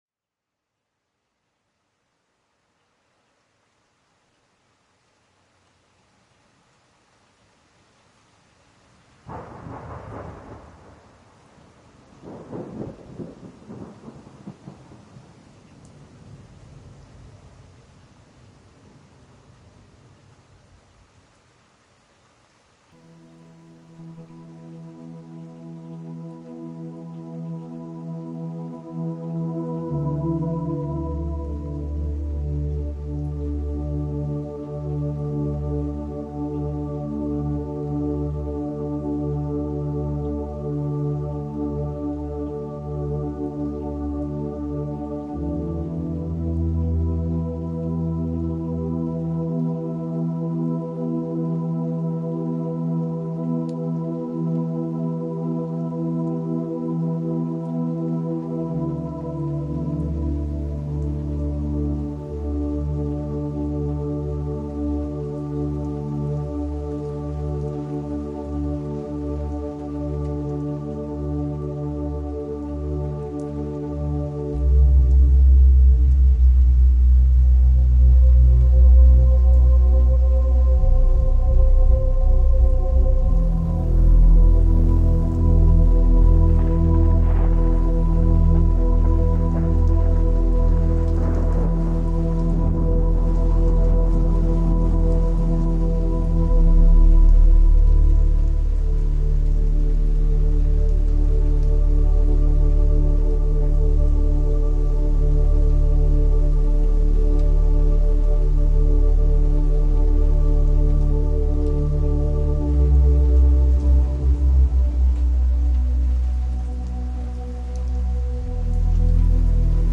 reimagined for a haunting techno-dystopian future.
A GenAI musical and lyrical adaptation